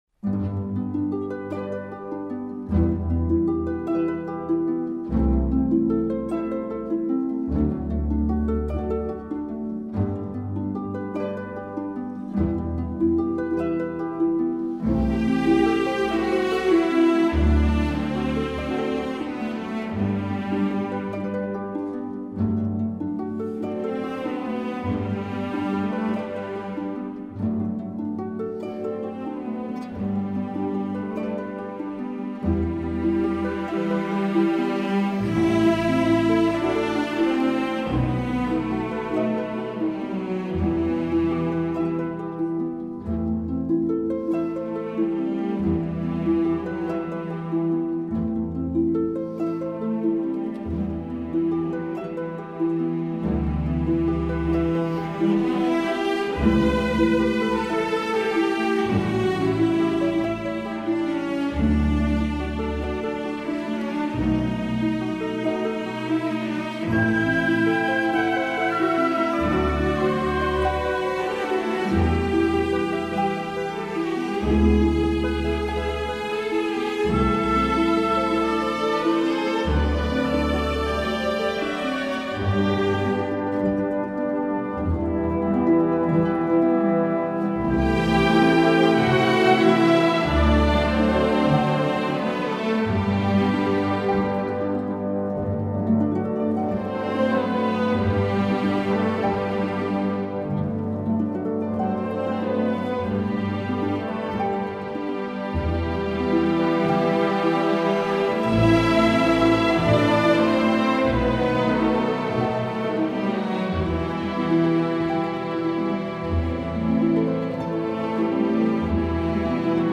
Chajkovskij_Adazhio_iz_baleta_SCHelkunchik_.mp3